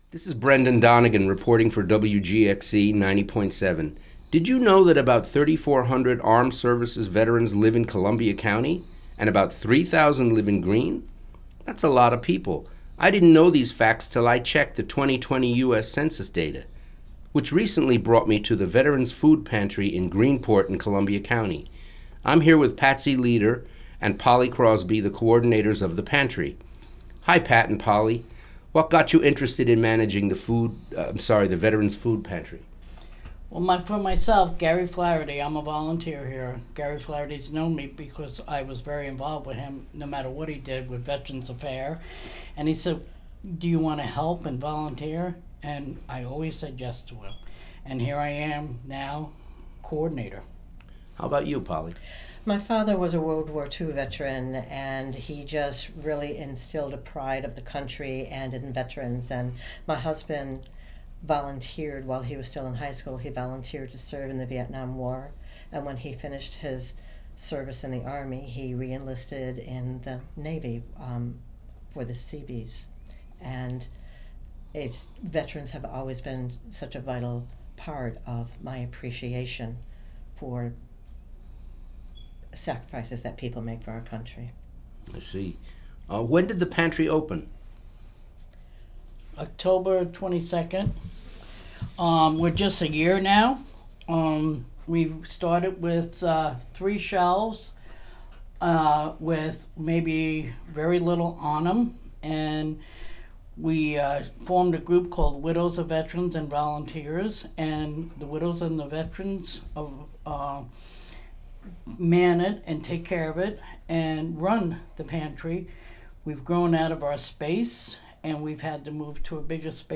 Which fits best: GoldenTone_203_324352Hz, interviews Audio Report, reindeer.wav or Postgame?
interviews Audio Report